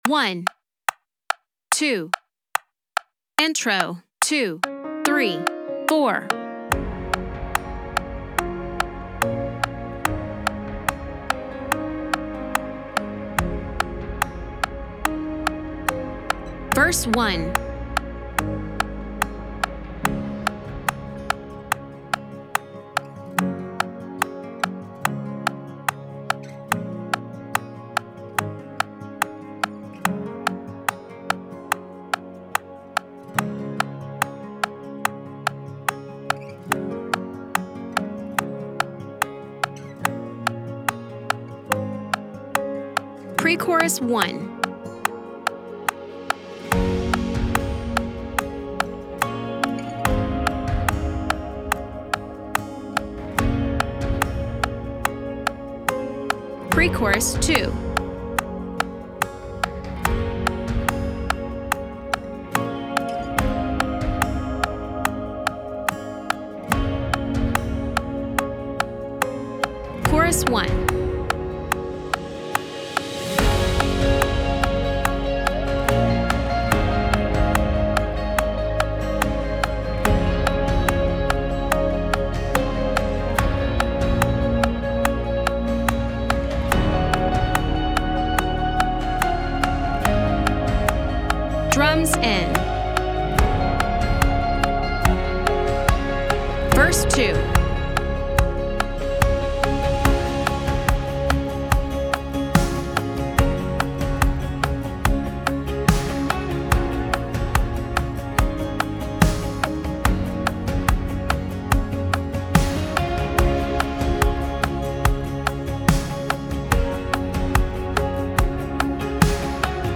Voor altijd heilig-Db - tracks and guide and click.mp3